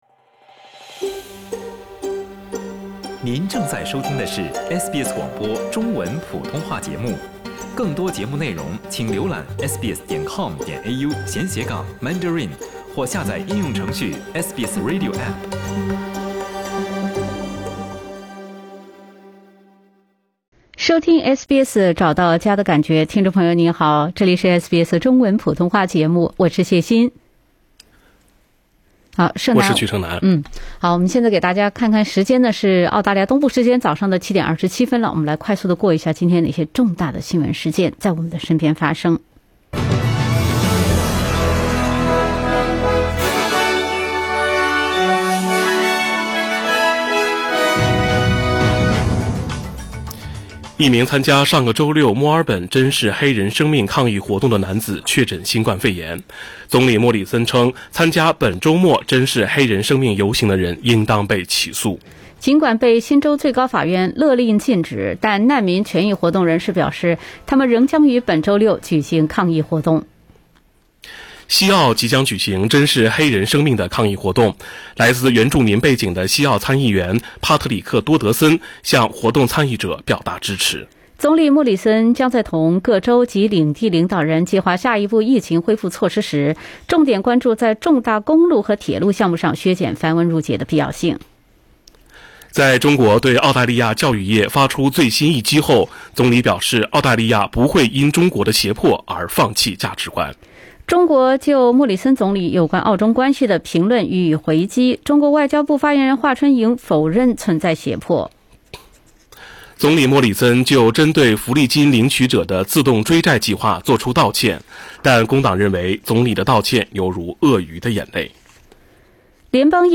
SBS早新闻（6月12日）
SBS Chinese Morning News Source: Shutterstock